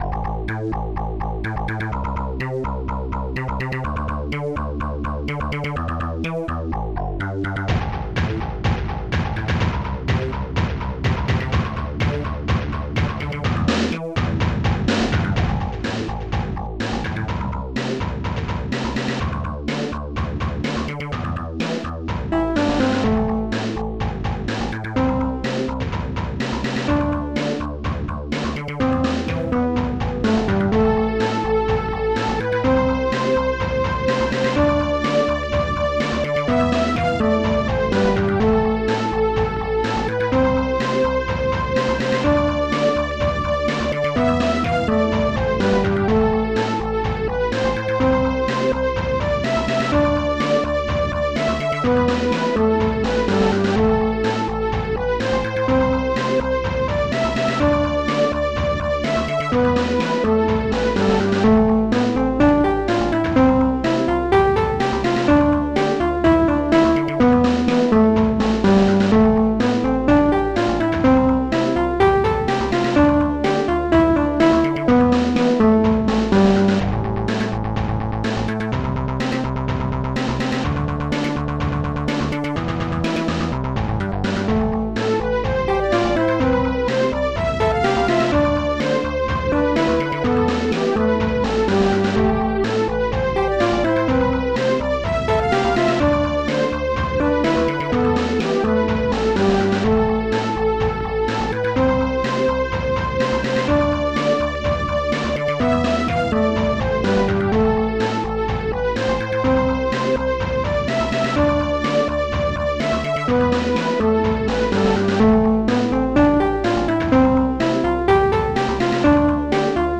st-02:growl st-02:ringtone st-01: st-01:ringpiano st-01:strings3 st-04:snx.drum3 st-04:snx.drum2